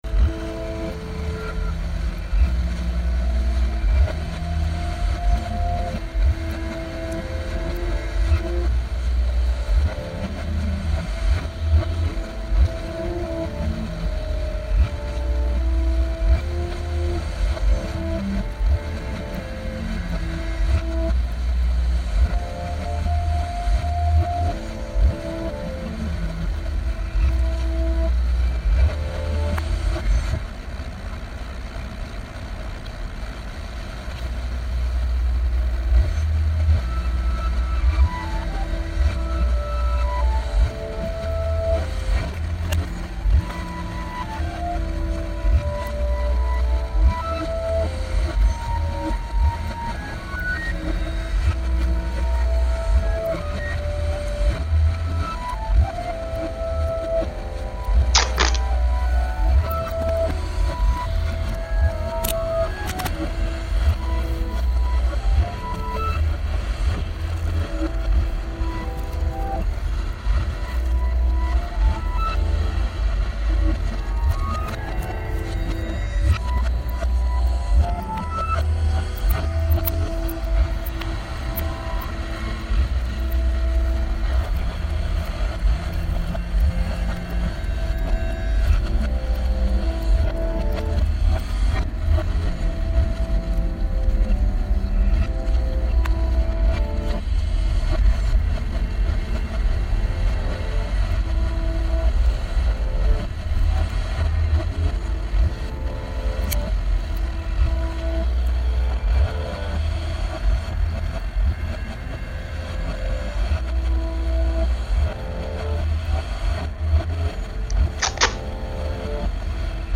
Performance for MONSTERA, Show One
With an experimental sound piece I created playing in the background [listen below], I respond with improvisational dance, cutting the flowers off of my body suit one by one, handing them to audience members.